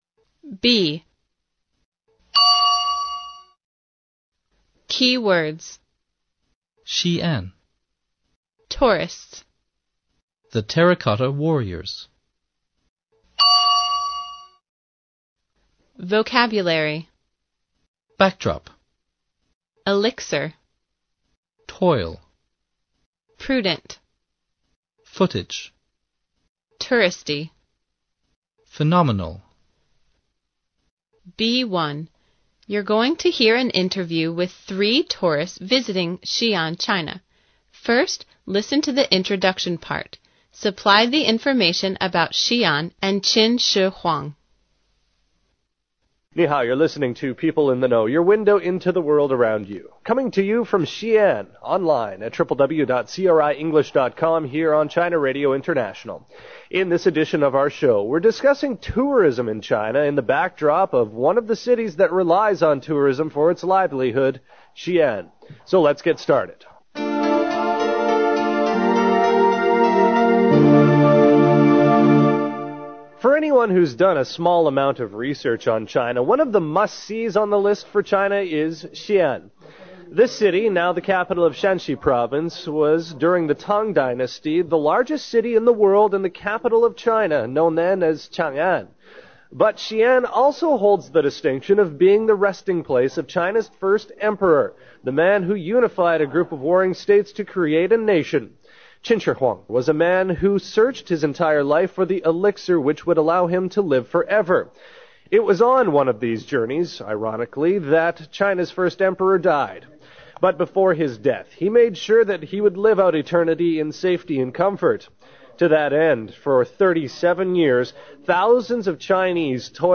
You're going to hear an interview with three tourists visiting Xi'an, China.